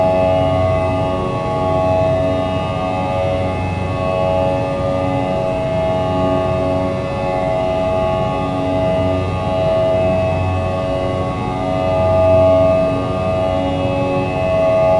v2500-buzz.wav